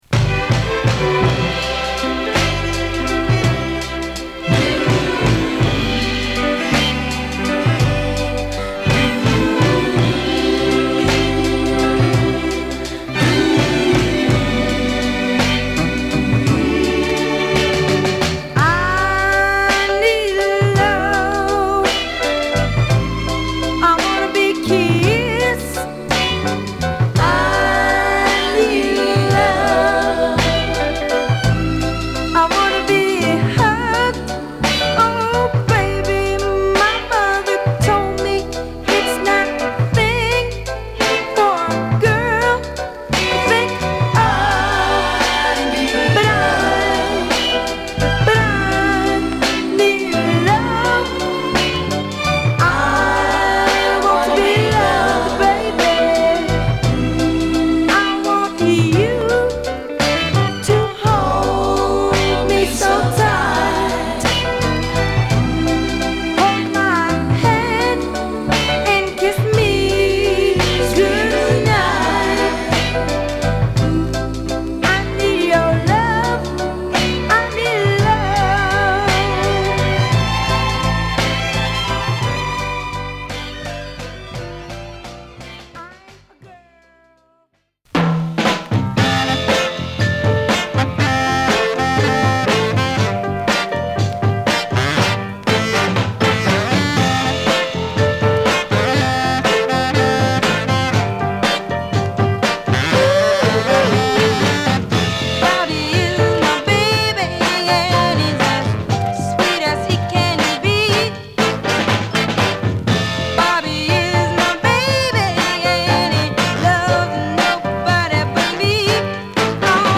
軽やかなリズムに晴れ晴れとしたヴォーカルが乗るシングルオンリーの